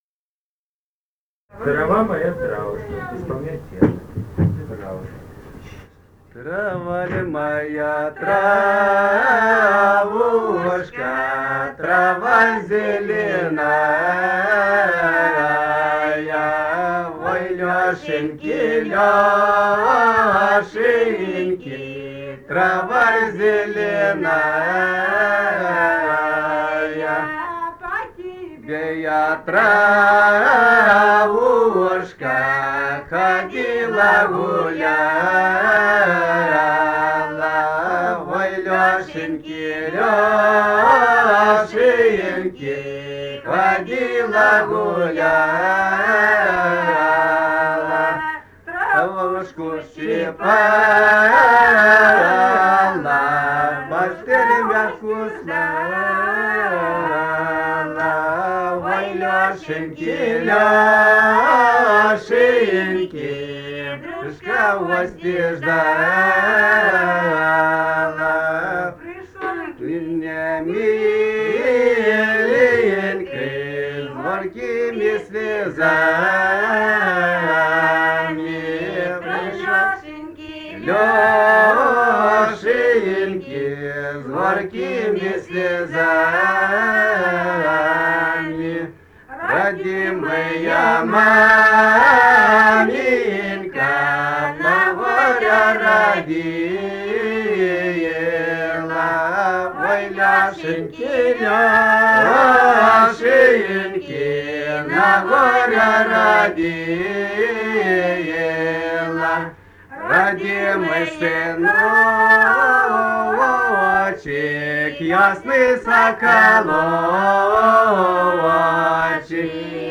Этномузыкологические исследования и полевые материалы
«Трава ль моя, травушка» (хороводная на масленицу).
Ставропольский край, пос. Терек Прикумского (Будённовского) района, 1963 г. И0717-09